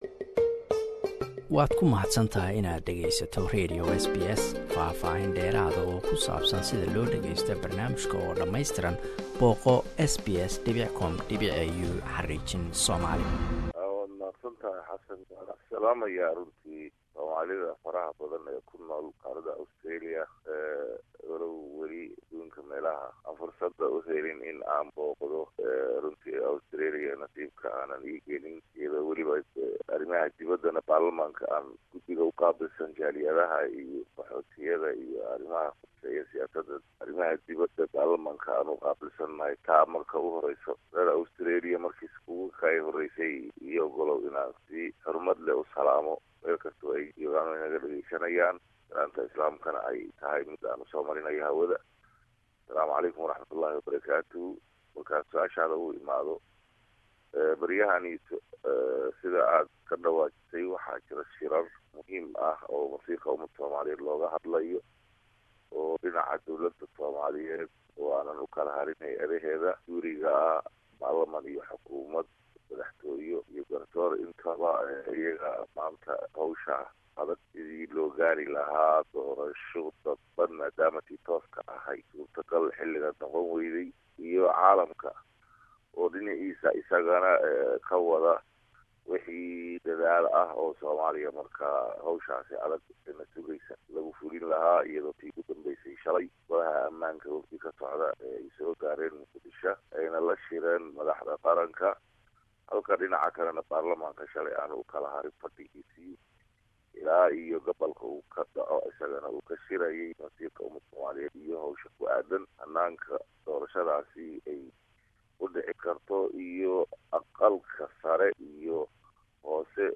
Interview: Professor and MP Mohamed Omar Dalha part 1